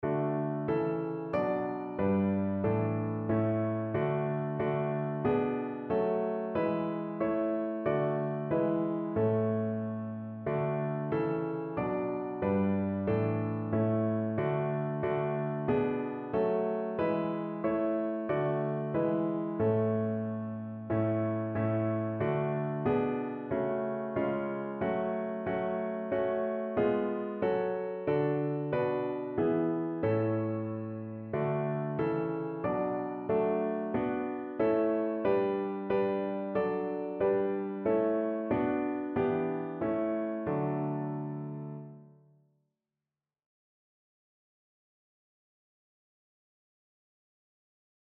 Evangeliumslieder
Notensatz 1 (4 Stimmen gemischt)